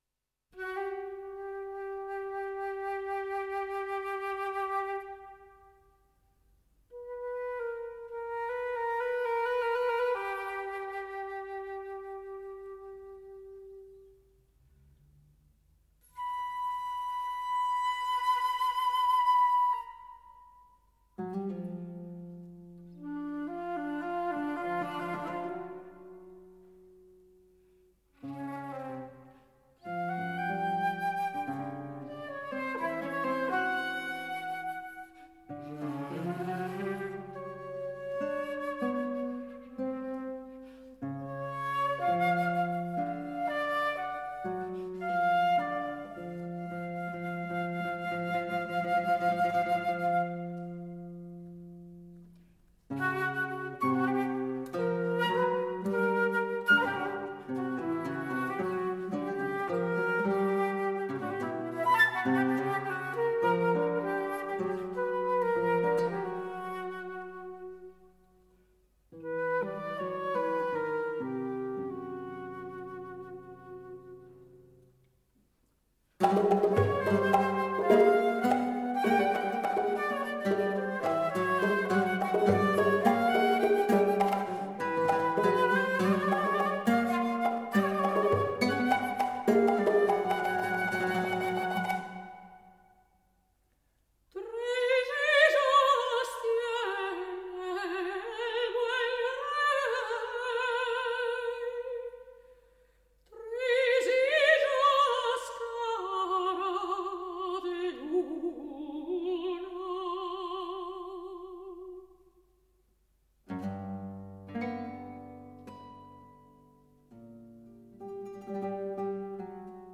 Tres hixas tiene'l buen rey melodia sefardita
mezzo-soprano, flute, guitar, percussion